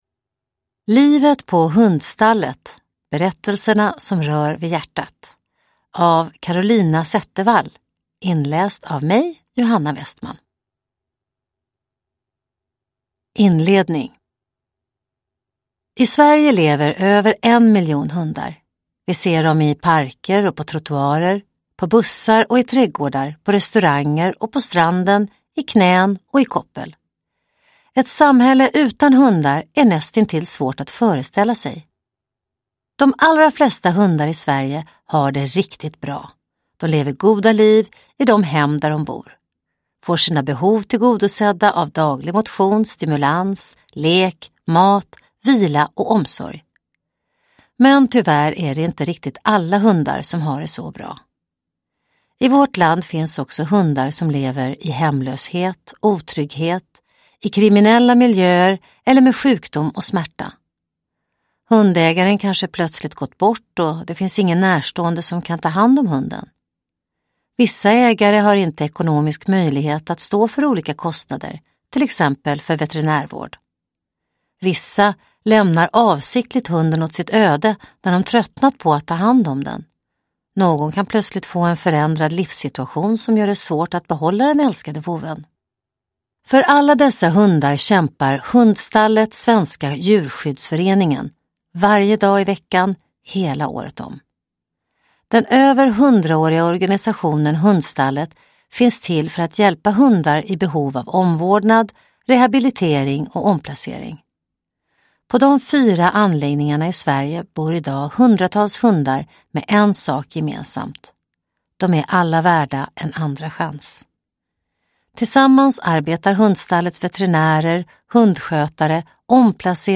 Uppläsare: Johanna Westman